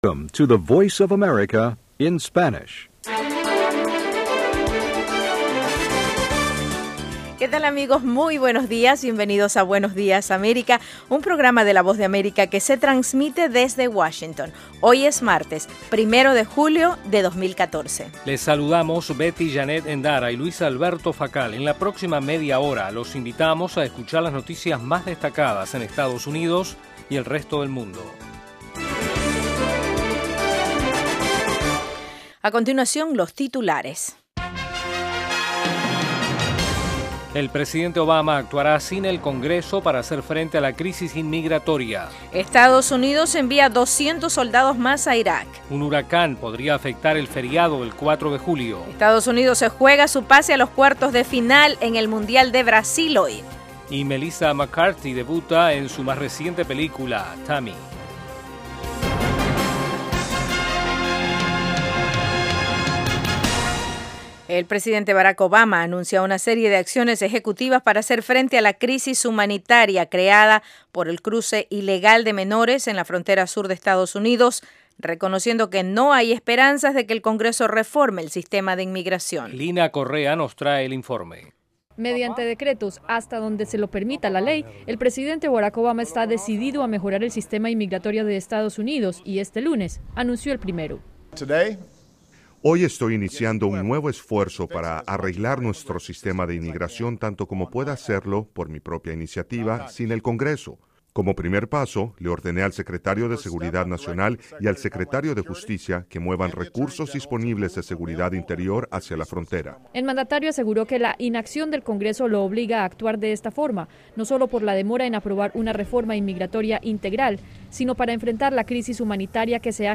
Buenos días América es un programa informativo diario de media hora dirigido a nuestra audiencia en América Latina. El programa se transmite de lunes a viernes de 8:30 a.m. a 9:00 a.m. [hora de Washington].